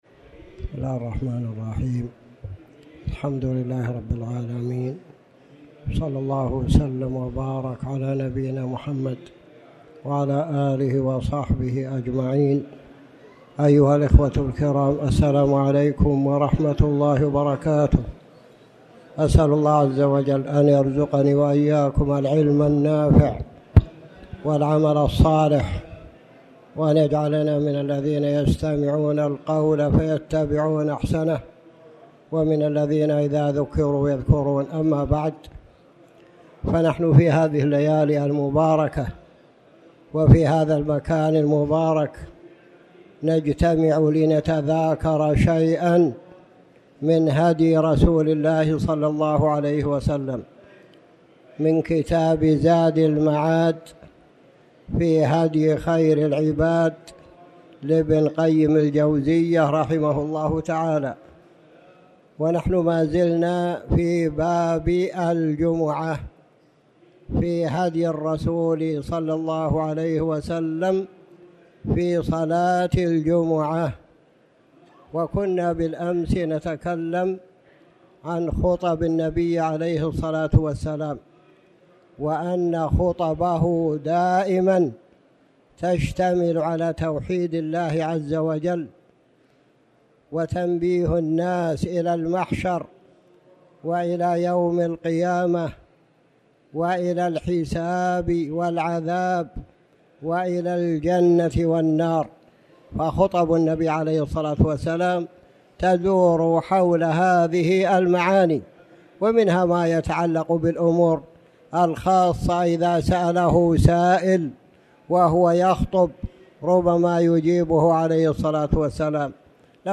تاريخ النشر ٢٥ ذو الحجة ١٤٣٩ هـ المكان: المسجد الحرام الشيخ